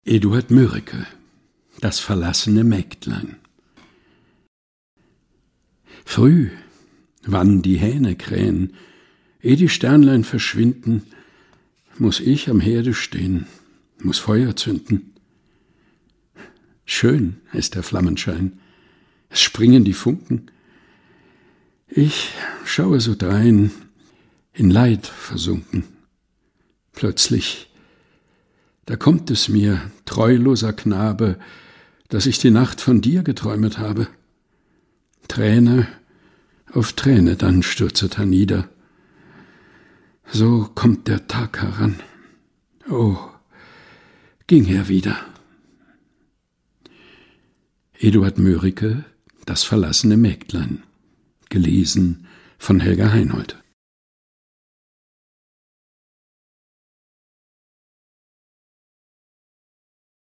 liest: Das verlassene Mägdlein - von Eduard Mörike.